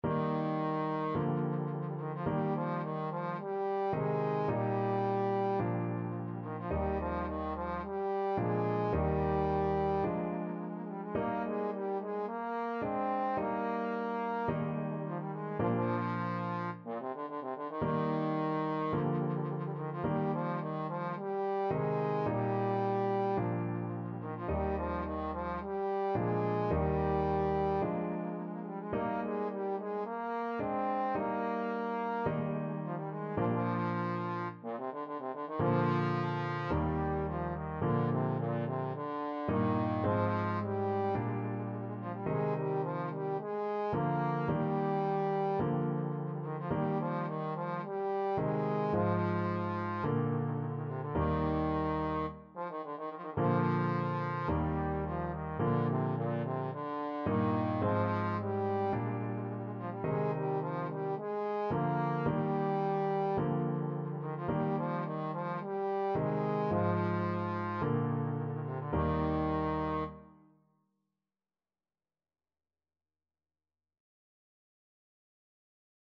Trombone
Eb major (Sounding Pitch) (View more Eb major Music for Trombone )
= 54 Slow
4/4 (View more 4/4 Music)
Bb3-C5
Classical (View more Classical Trombone Music)